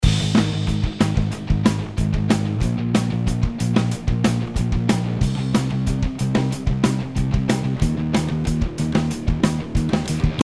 前回紹介し忘れてたんですけど、ギターにもSaturn 2をかけていました。
元の音：
前者はオケに埋もれた感じがいていましたが、Saturn 2をかけることによって音が結構前に出てくる感じがしますね。